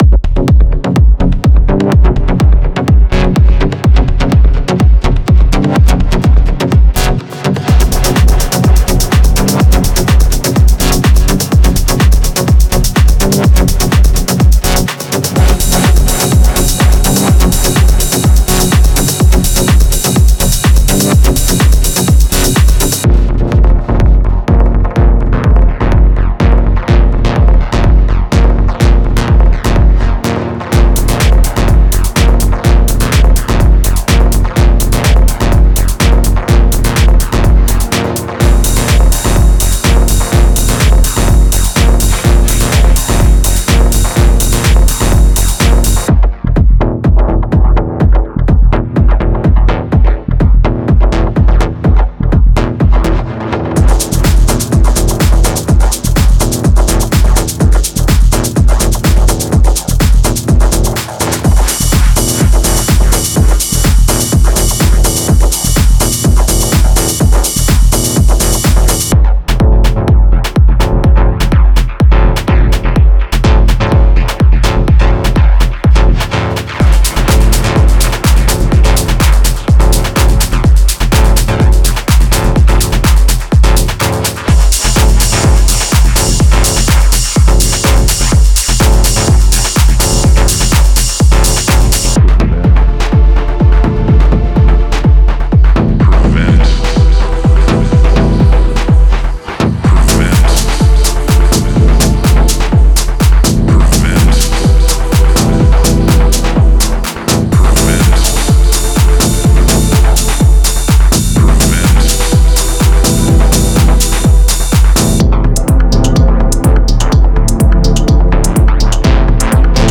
デモサウンドはコチラ↓
95 Drum loops (Full, Kick, Clap, Hihat, Perc, Ride)
10 Vox loops
10 Pad loops (Key labelled)